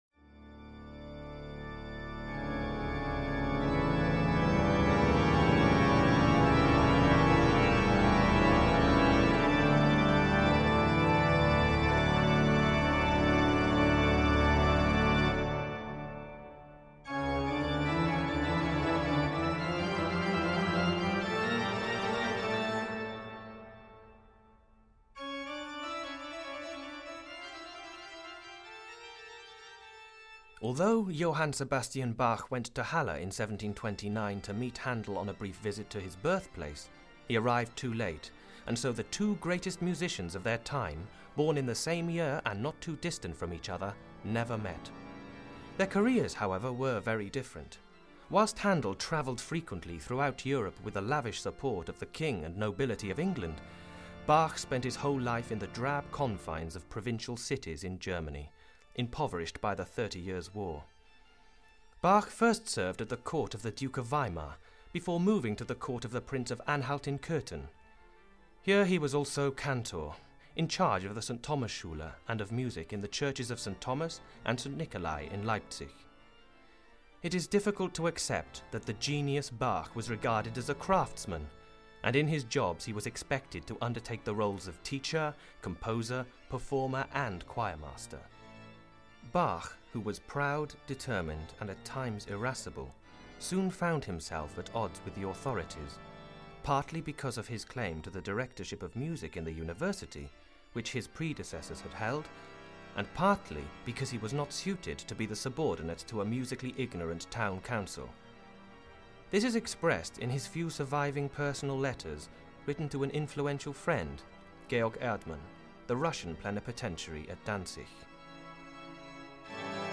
Ukázka z knihy
In Composers’ Letters, the voices of the great figures of classical music come alive through their correspondence. Set against the music we know and love, Mozart, Beethoven, Schubert, Wagner, Tchaikovsky and many more talk openly about their music, their hopes and fears, their love, their sadness and their struggles in realising their artistic hopes in a commercial world.